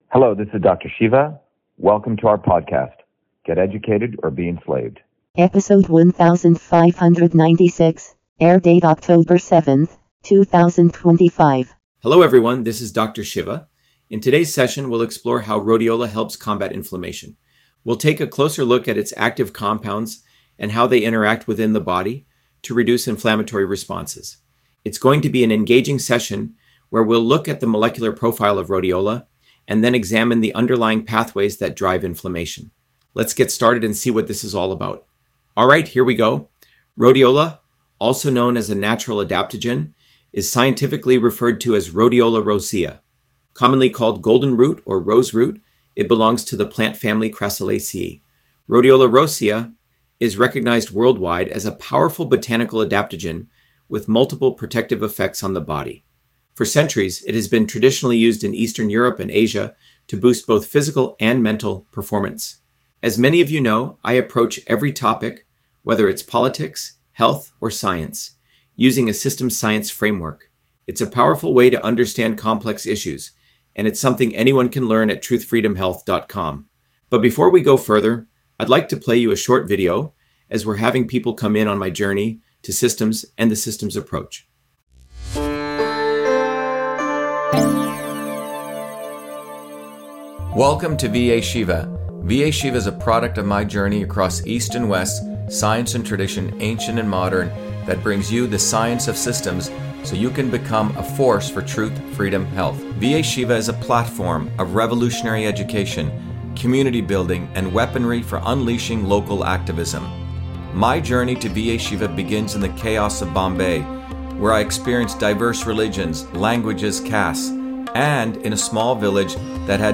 In this interview, Dr.SHIVA Ayyadurai, MIT PhD, Inventor of Email, Scientist, Engineer and Candidate for President, Talks about Rhodiola on Inflammation: A Whole Systems Approach